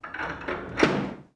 Cerrar el portón de madera de un palacio